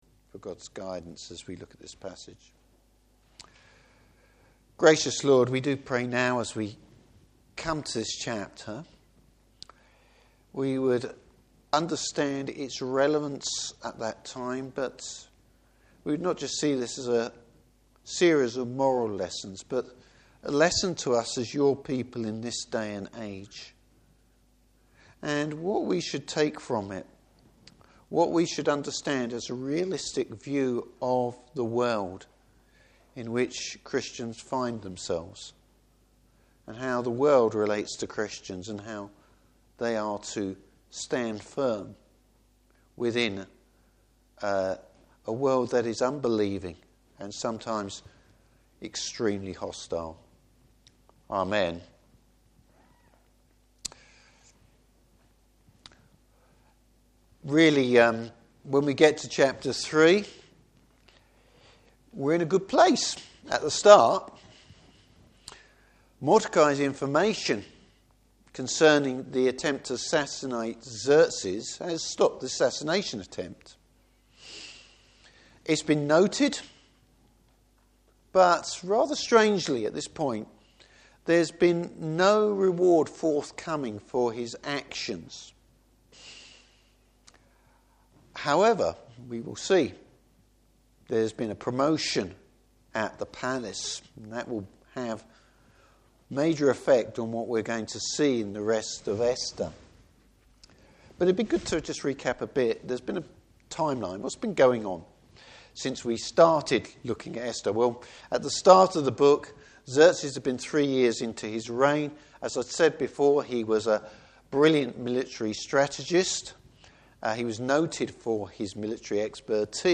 Service Type: Evening Service A threat to God’s people.